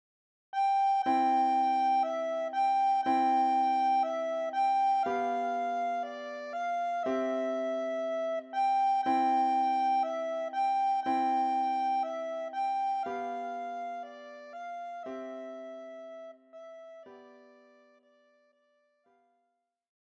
für Sopranblockflöte (Klavierbegleitung Level 2/10)